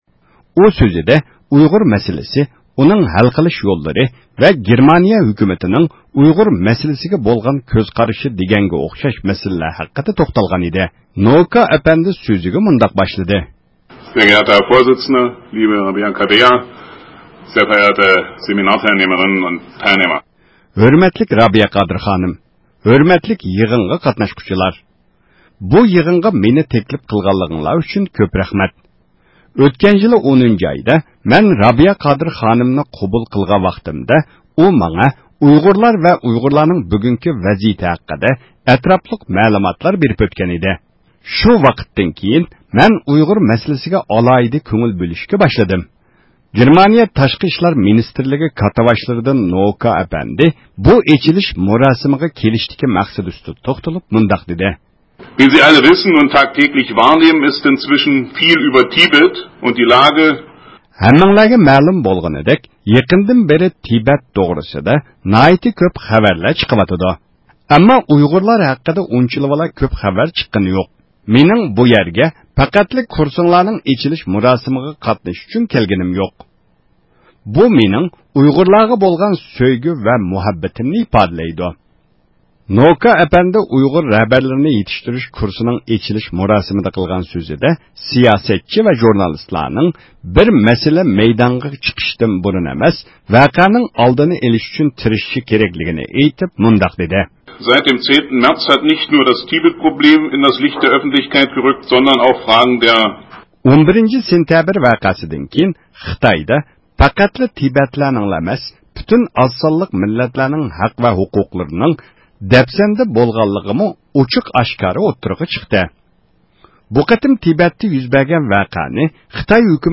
گېرمانىيە پايتەختى بېرلىندا ئۆتكۈزۈلگەن دۇنيا ئۇيغۇر قۇرۇلتىيى، ۋاكالەتسىز مىللەتلەر تەشكىلاتى ۋە ئامېرىكىنىڭ دېموكراتىيىنى ئىلگىرى سۈرۈش فوندى بىرلىكتە تەشكىللىگەن 2 – نۆۋەتلىك ئۇيغۇر رەھبەرلىرىنى دېموكراتىيە ۋە ئىنسان ھوقۇقلىرى بويىچە تەربىيىلەش كۇرسىدا گېرمانىيە تاشقى ئىشلار مىنىستىرلىكى مەسئۇلى نوئوكا ئەپەندىمۇ سۆز قىلغان ئىدى.